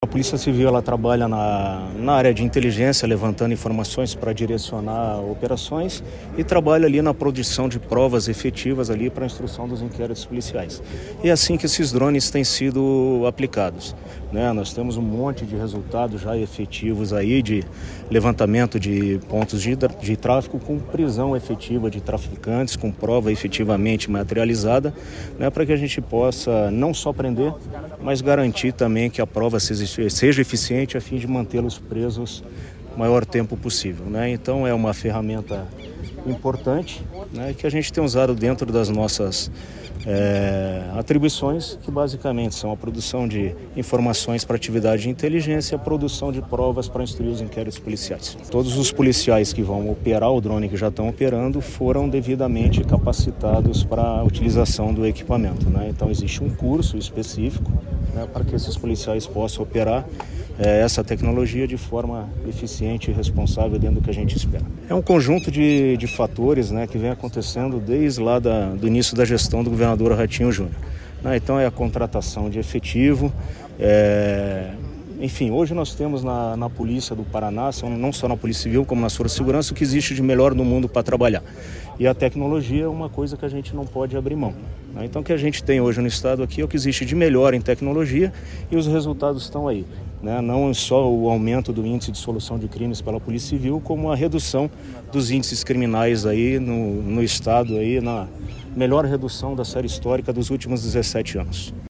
Sonora do delegado-geral da PCPR, Silvio Rockembach, sobre a entrega de 243 drones para monitoramento aéreo das forças de segurança do Paraná